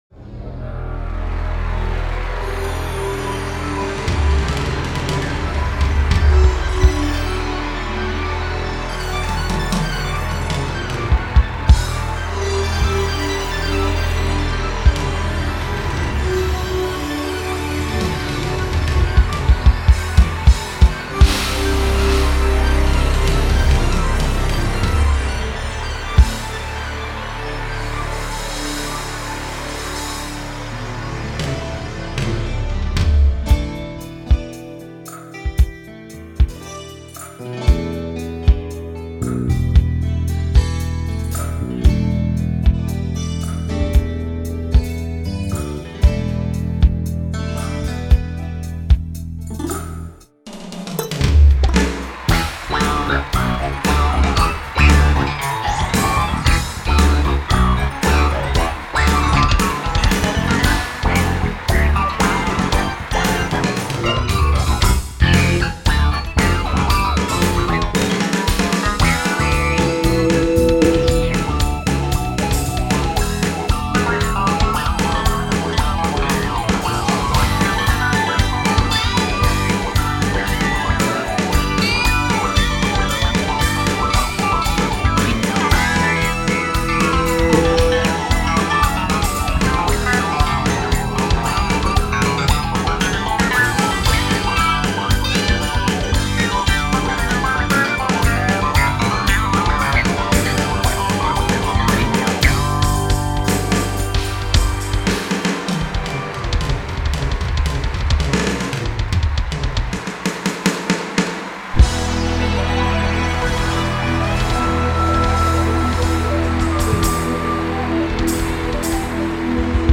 Acoustic Modeling with SX-WSA1R
technics_sx-wsa1r_-_factory_demo_-_qsdisk_killer.mp3